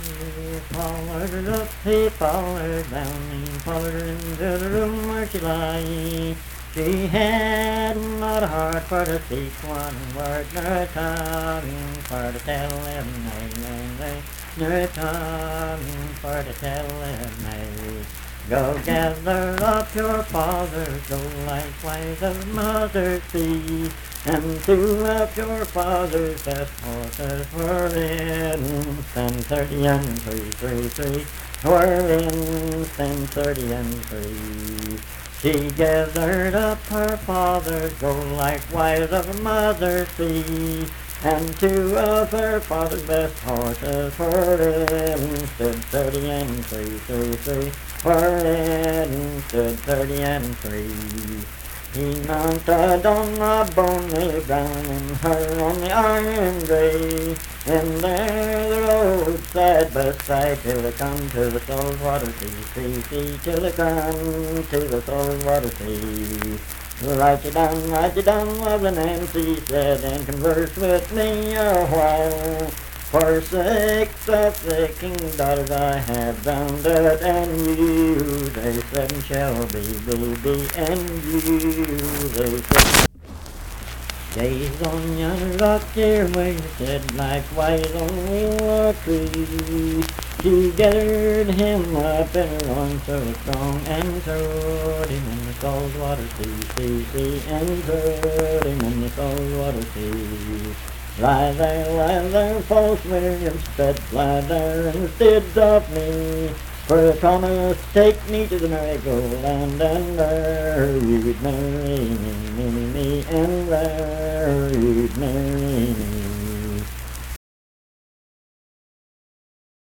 Unaccompanied vocal music
Performed in Dundon, Clay County, WV.
Voice (sung)